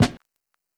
Snare normal MASSA.wav